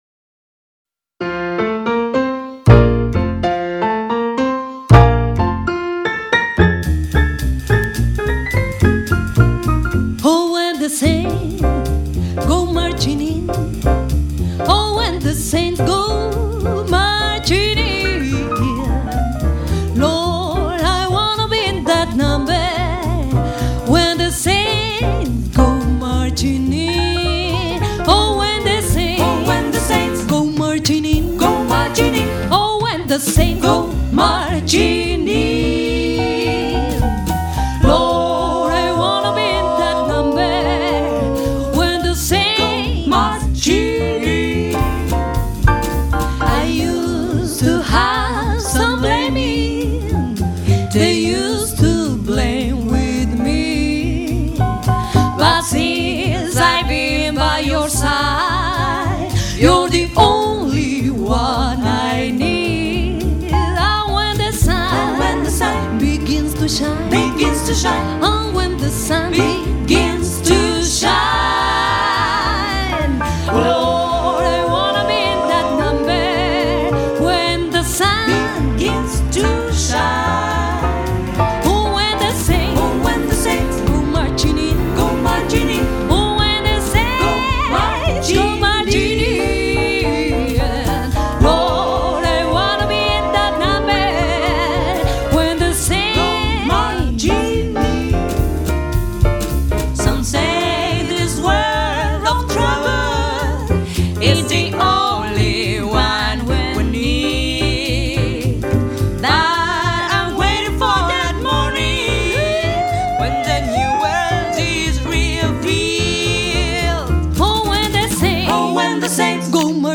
Cuarteto Fem.mp4